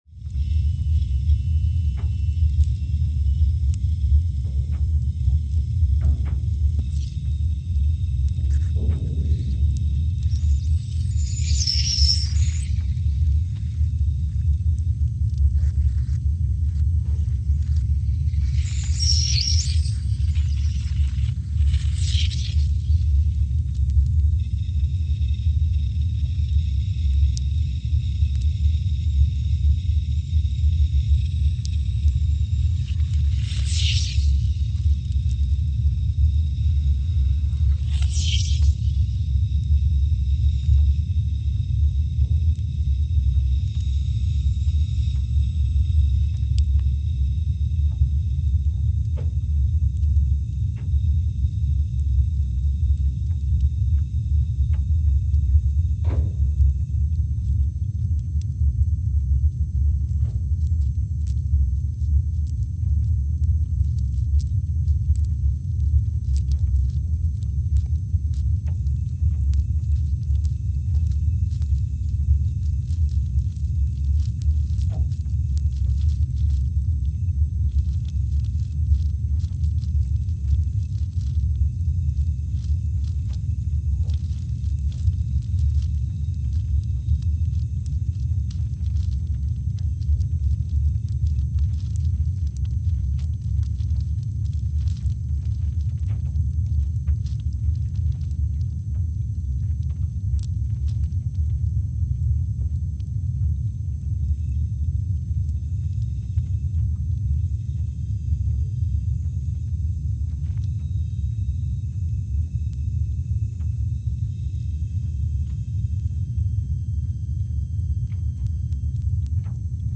Scott Base, Antarctica (seismic) archived on May 18, 2021
Sensor : CMG3-T
Speedup : ×500 (transposed up about 9 octaves)
Loop duration (audio) : 05:45 (stereo)
Gain correction : 25dB